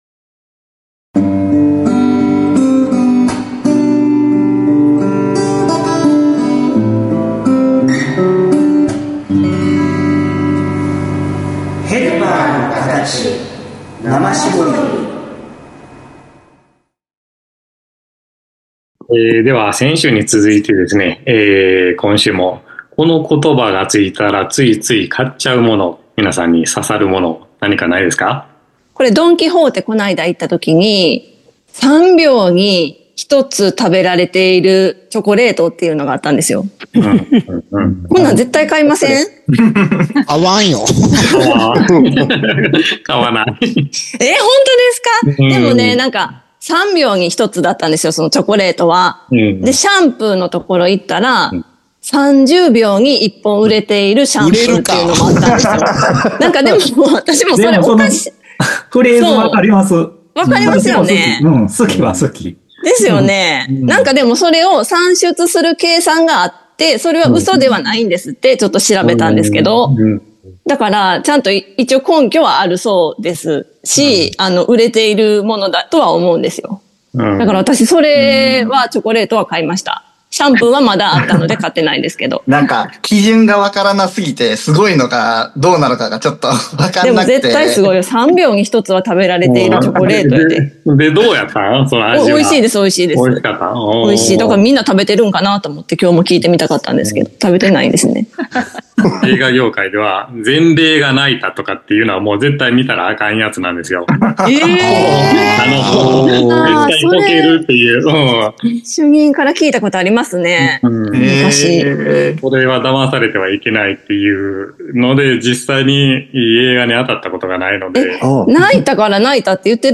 ＜今週のテーマ＞ ４週企画の第２弾を配信 致します。思わず釣られて しまうコピーや宣伝文句に ついてのクロストークを お楽しみ下さいませ！